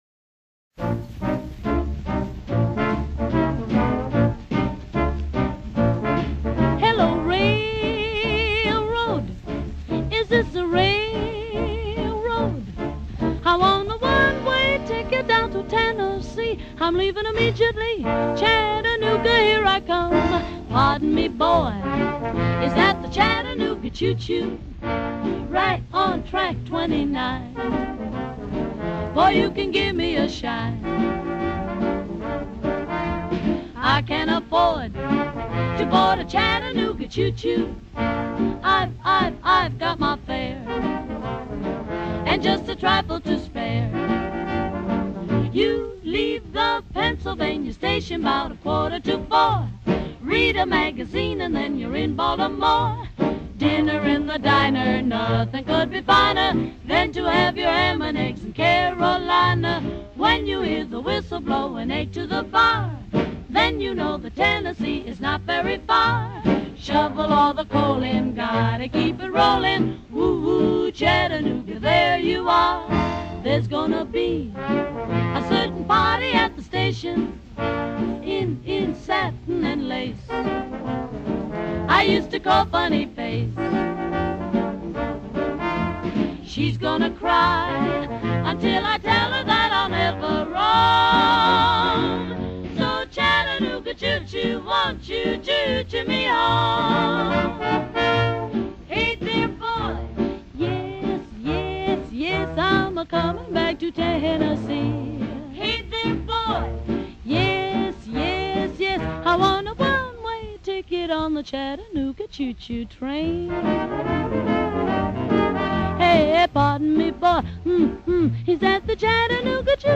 big band sound